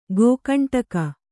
♪ gōkaṇṭaka